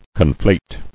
[con·flate]